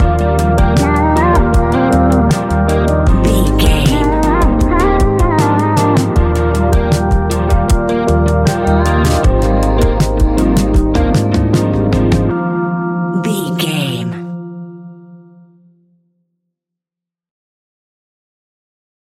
Ionian/Major
F♯
chilled
laid back
Lounge
sparse
new age
chilled electronica
ambient
atmospheric
morphing